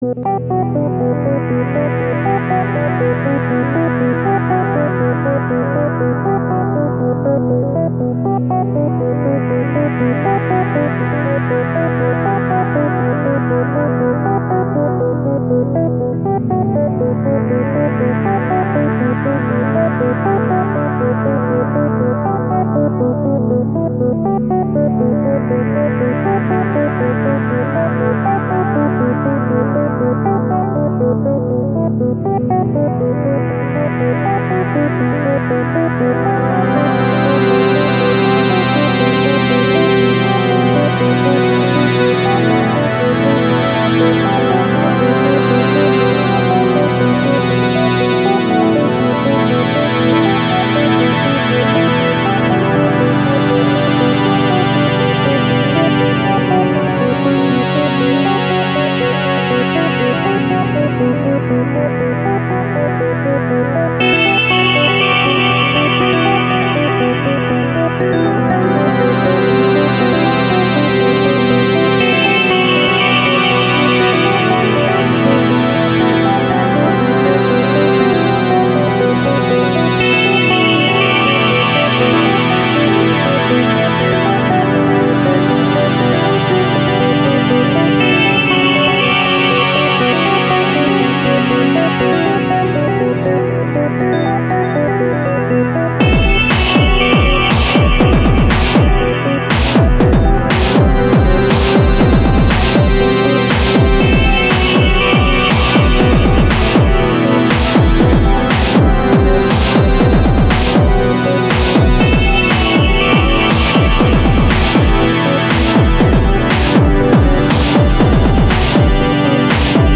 Nice and catchy, too!
Kinda reminds me of Terminator 1, like at the start mixed with some other stuff, mainly those heavy synths that did it, then it changes, but is still very cool, good work! The drums made it a very nice little tune.
Reminds me a bit of Metroid with some techno thrown in.
Tasty stuff, I like it, especially the way the percussion & bass fit with the synth.